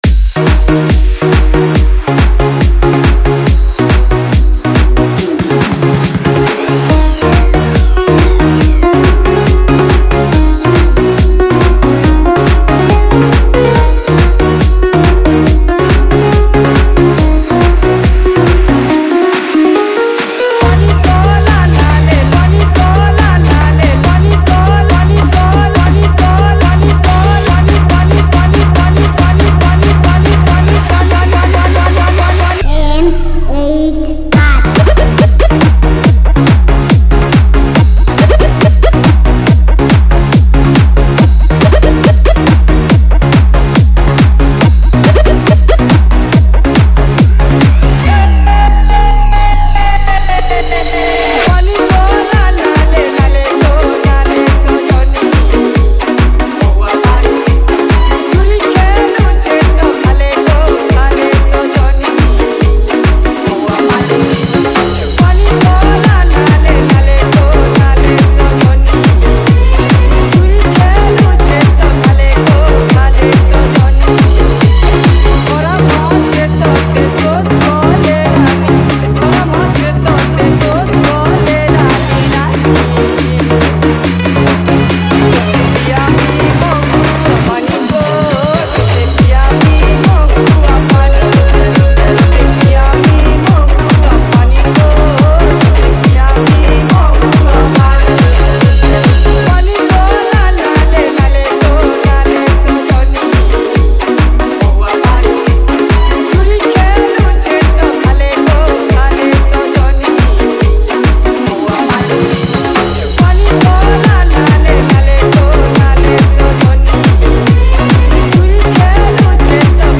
Category:  Sambalpuri New Dj Song 2019